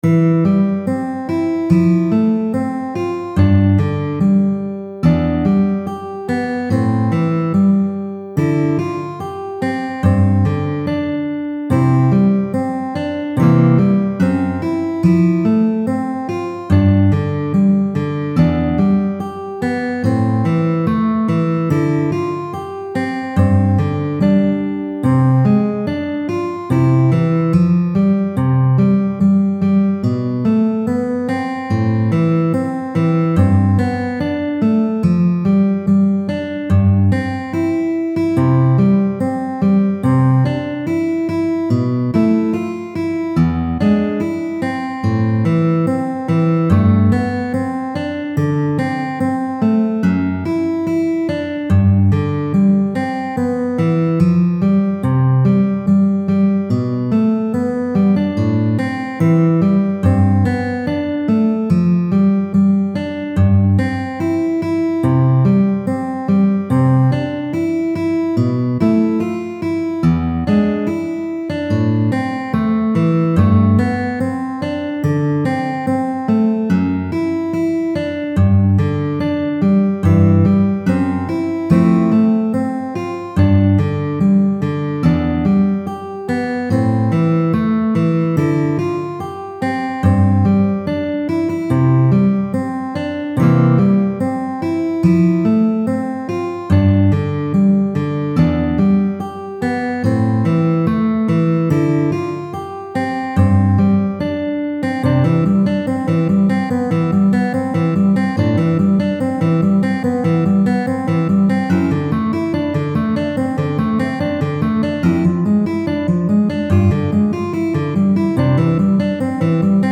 C调指法